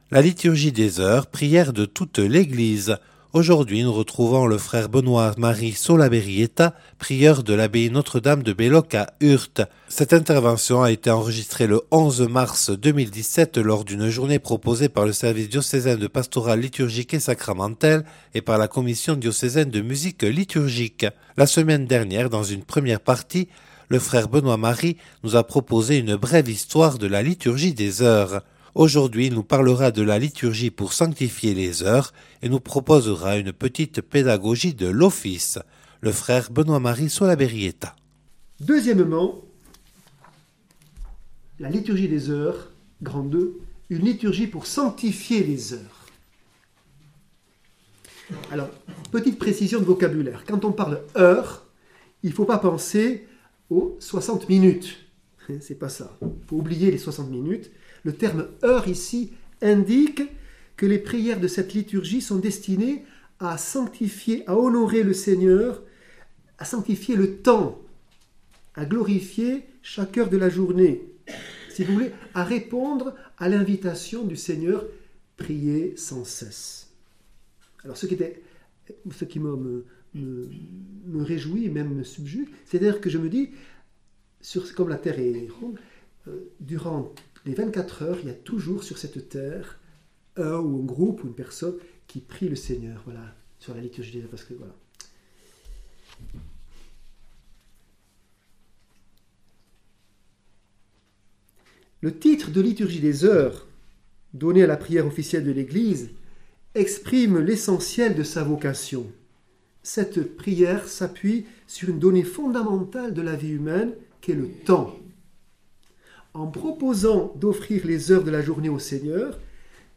(Enregistré le 11/03/2017 à Belloc lors de la journée de formation proposée par le Service diocésain de Pastorale Liturgique et Sacramentelle et la Commission diocésaine de Musique liturgique).